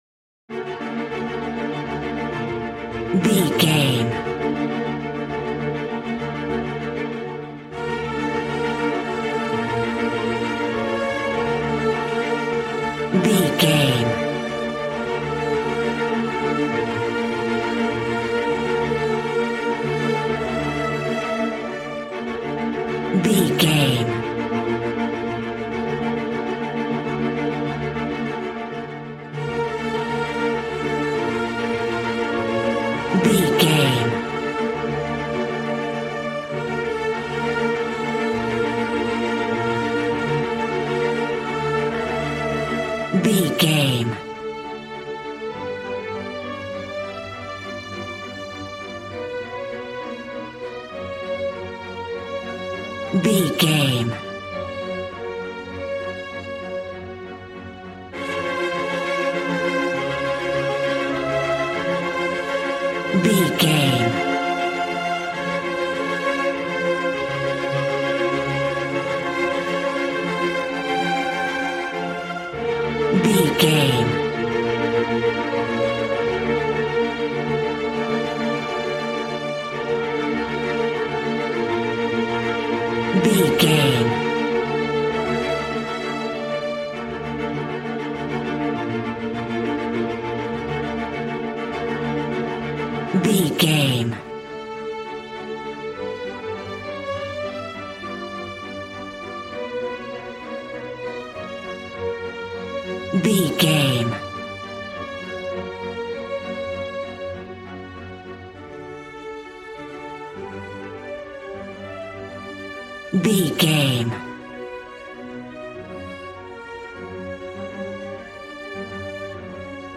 Ionian/Major
B♭
regal
strings
brass